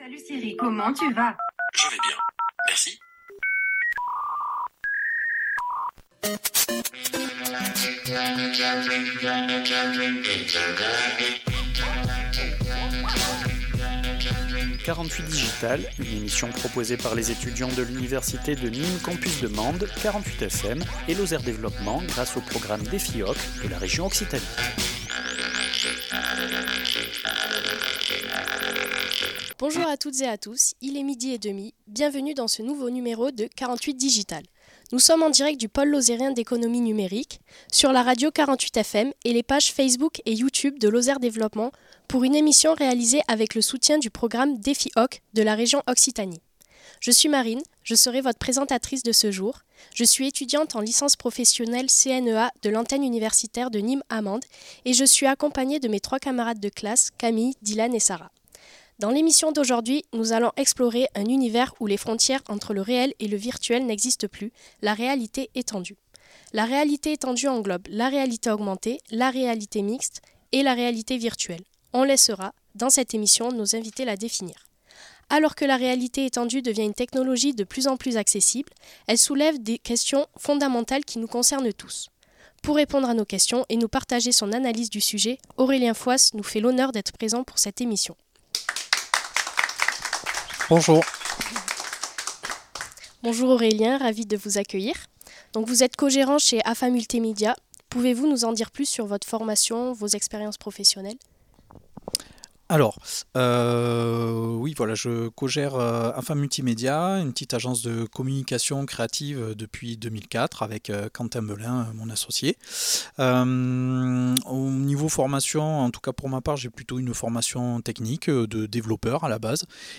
Une émission proposée par 48FM, l’Université de Nîmes antenne de Mende et Lozère développement